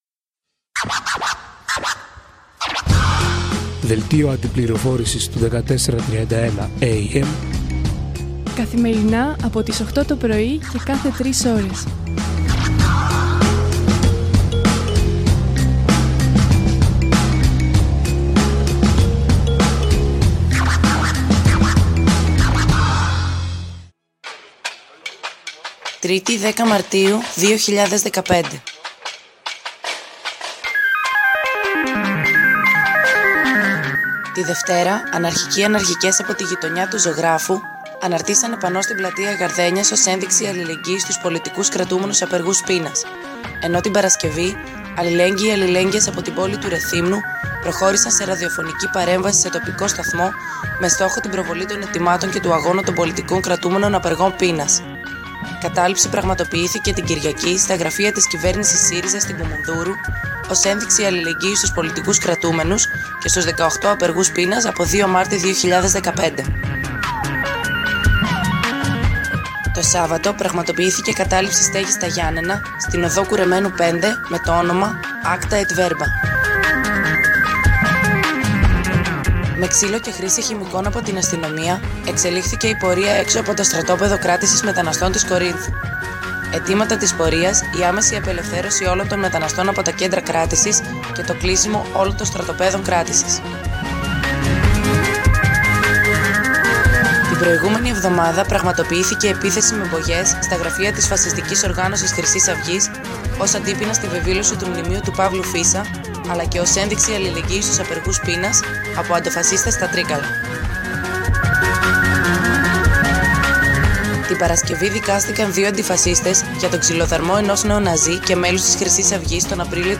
Δελτίο Αντιπληροφόρησης